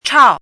怎么读
chào miǎo